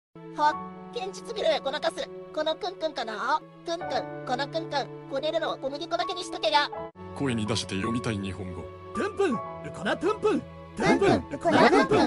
fen punpun Meme Sound Effect
This sound is perfect for adding humor, surprise, or dramatic timing to your content.